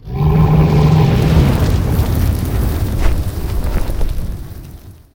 bellow.ogg